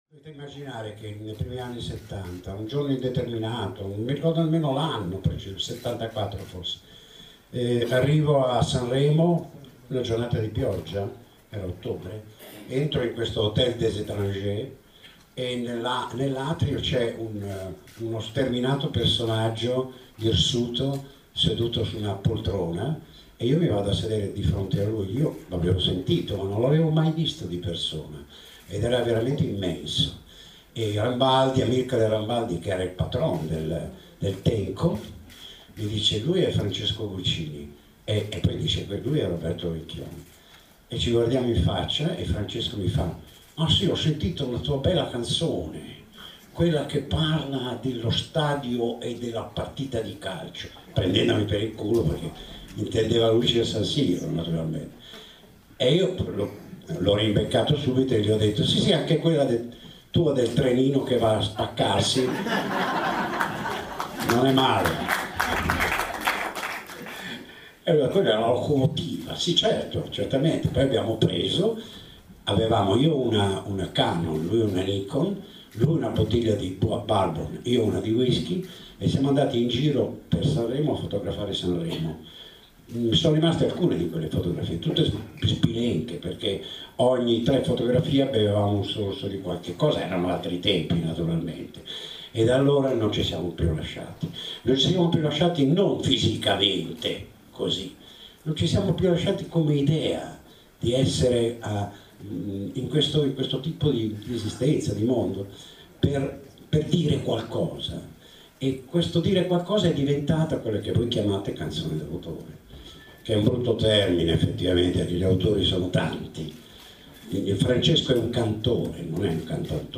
Rivederlo oggi al Teatro Gerolamo di Milano è stato come respirare di nuovo. Intelligenza, ironia, parole di valore, più che una conferenza di presentazione del nuovo disco L’infinito , in uscita venerdì, una lectio magistralis alla quale ho assistito a bocca e cuore aperti.